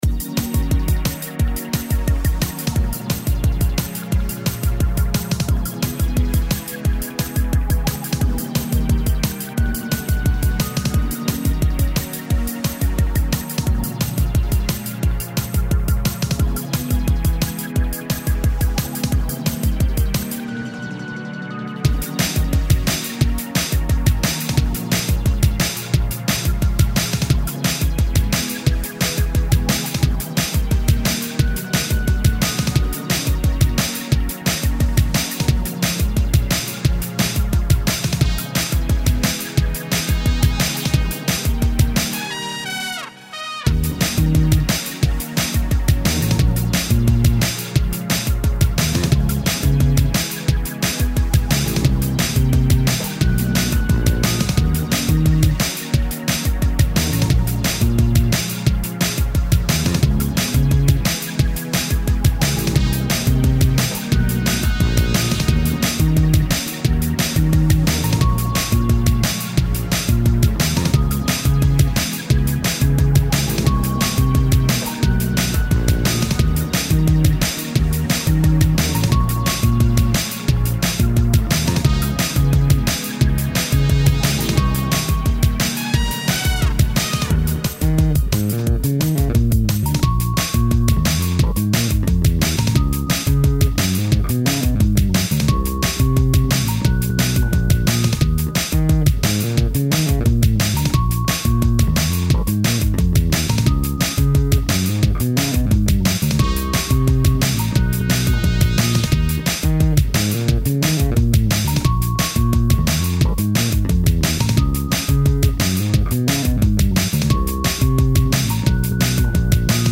Drum and bass tune with trumpets and lots of bass guitar...